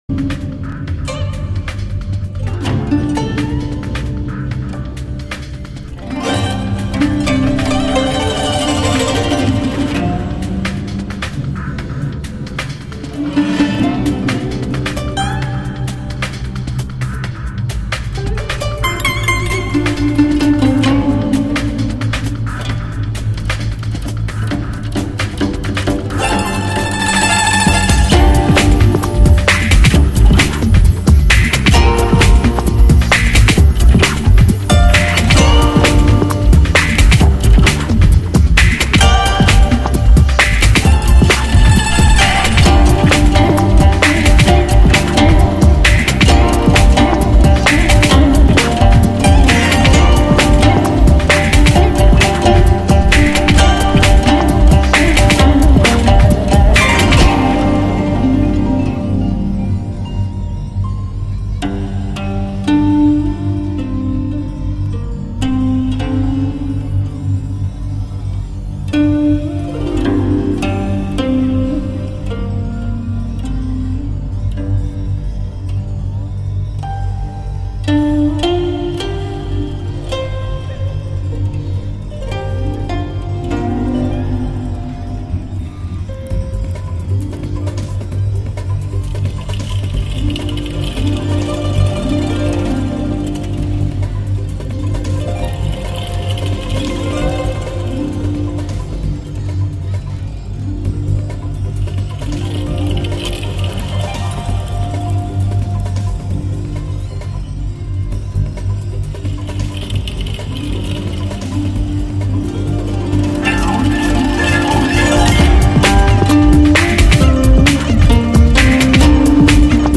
背景音乐二